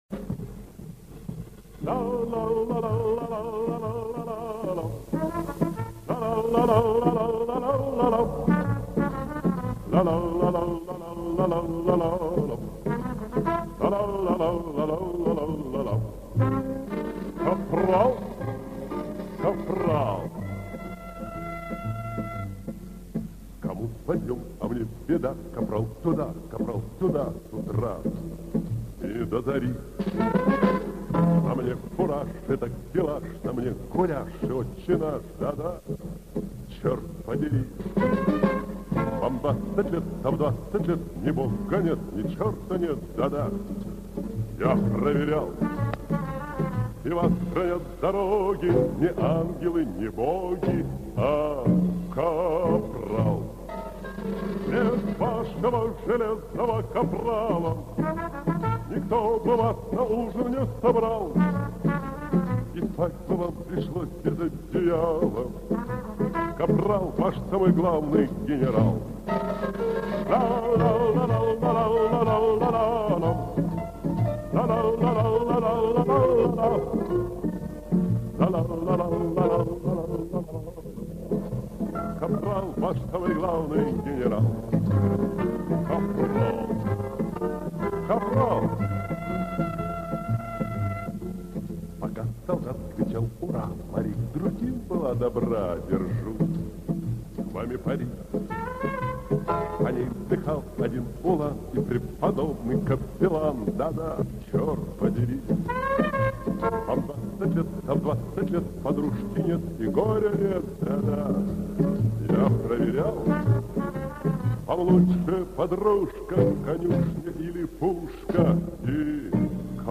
правда в мусорном качестве.